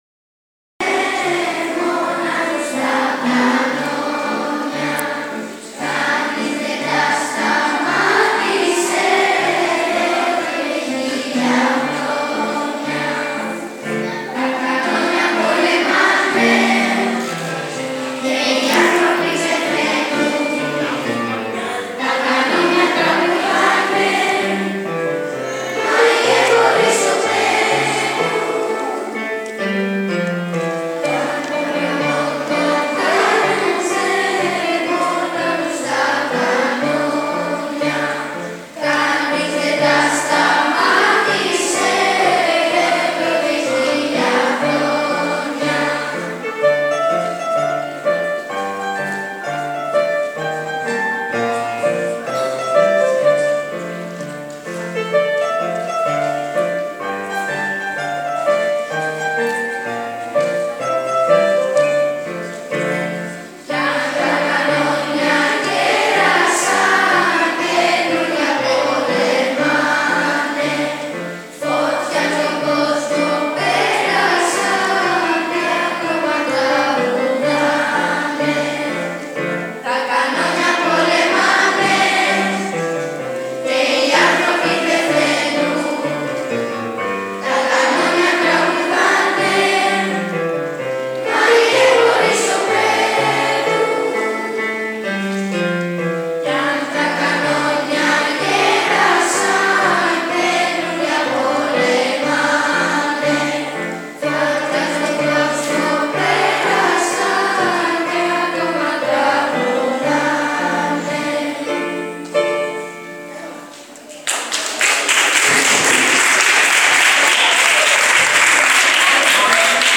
Την Παρασκευή οι μαθητές μας τίμησαν την Εθνική Επέτειο της 28ης Οκτωβρίου με διάφορες δραστηριότητες στο χώρο του σχολείου.
ΤΡΑΓΟΥΔΙ-ΧΟΡΩΔΙΑΣ.mp3